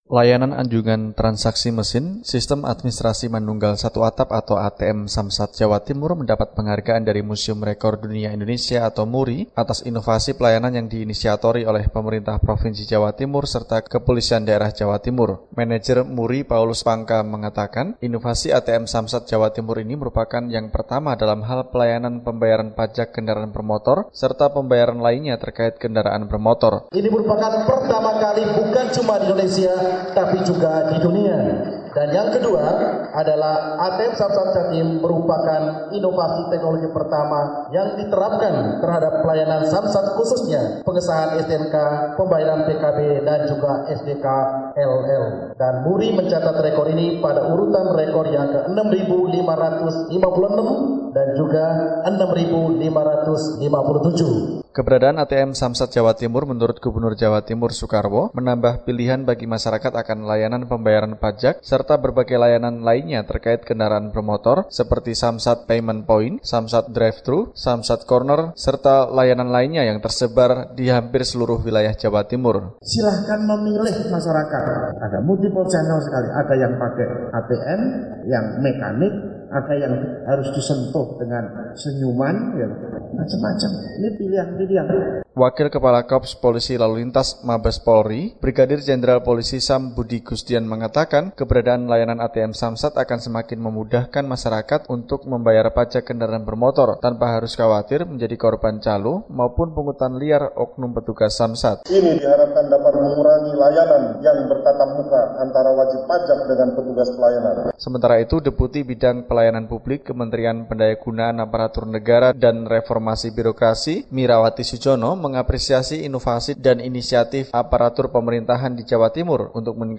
melaporkannya dari Surabaya, Jawa Timur.